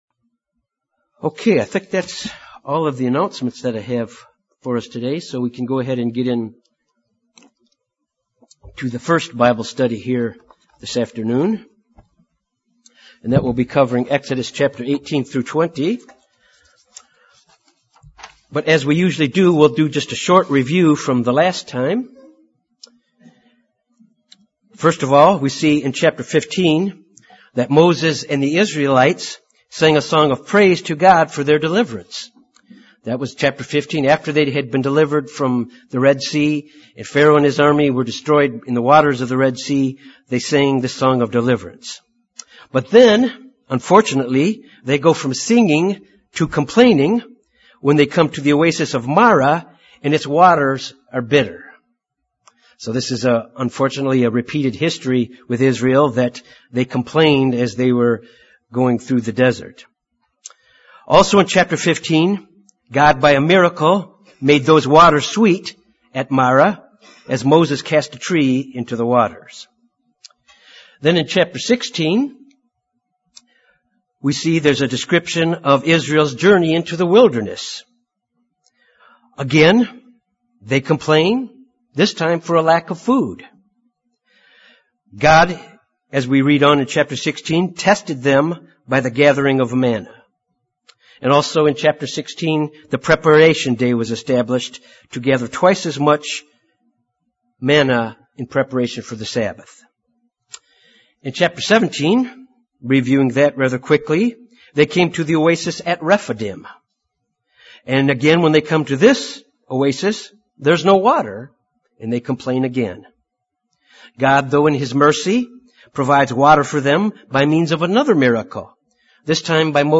This Bible Study covers the travels of the Israelites to Mt Sinai were God proposes a covenant between Him and the Children of Israel.
Given in Jonesboro, AR Little Rock, AR